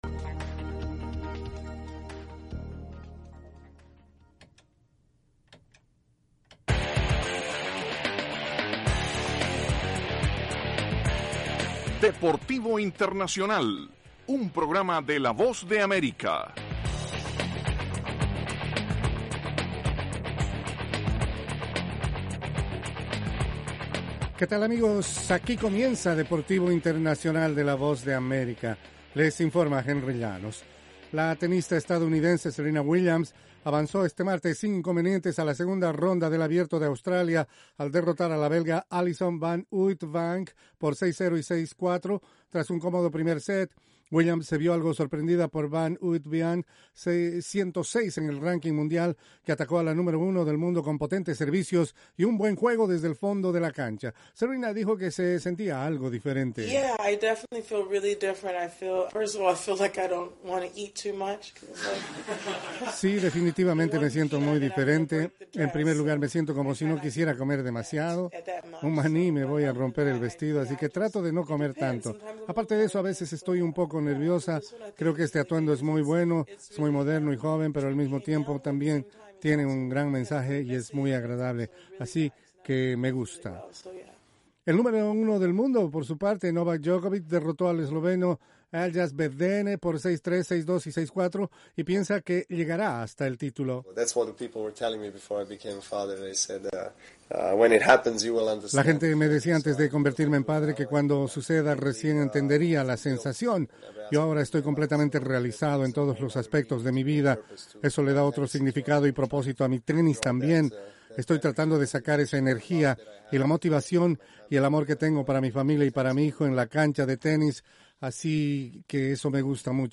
las noticias más relevantes del mundo deportivo desde los estudios de la Voz de América. Deportivo Internacional se emite de lunes a viernes, de 12:05 a 12:10 de la tarde (hora de Washington).